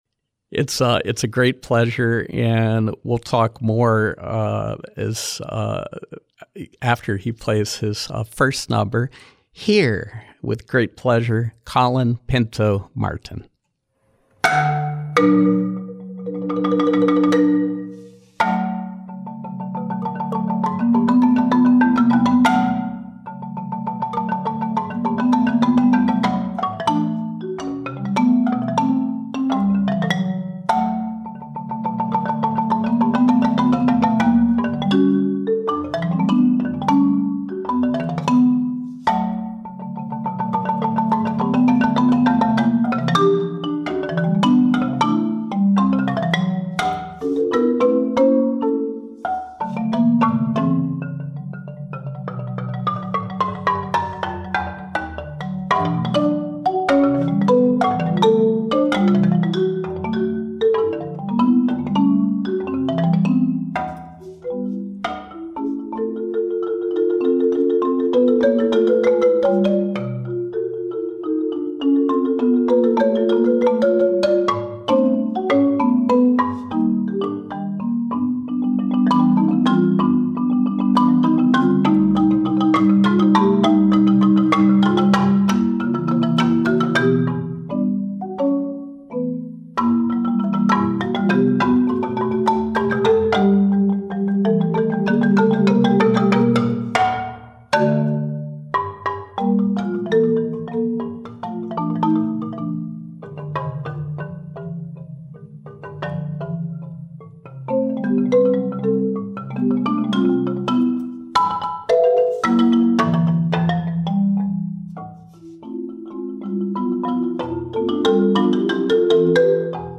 Live Music: Handmade Percussion
Live music with the unique sounds